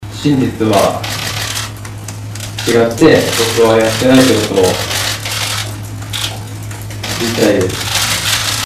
※アクセントも発音もおかしいが、完全否定を行っている可能性のある、注目すべきリバース・スピーチである。